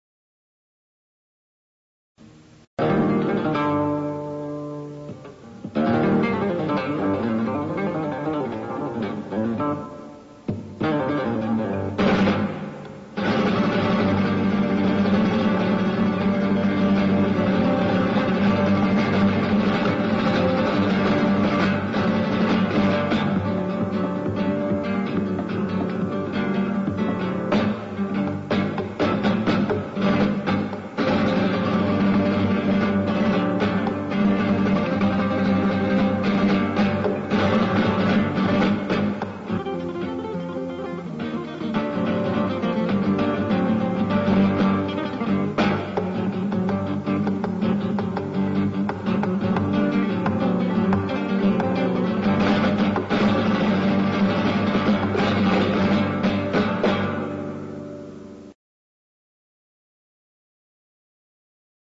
FLAMENCO